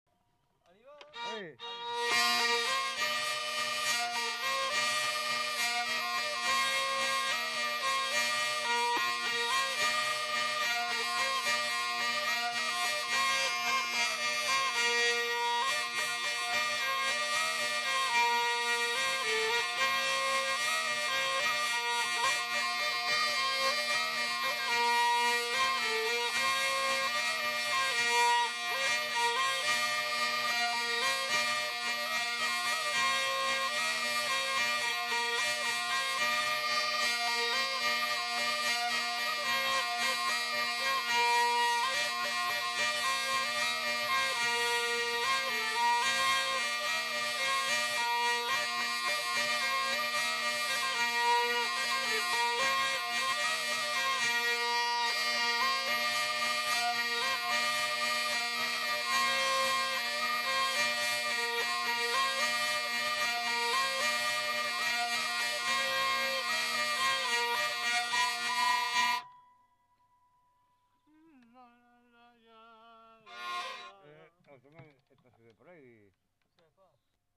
Valse
Aire culturelle : Gabardan
Genre : morceau instrumental
Instrument de musique : vielle à roue
Danse : valse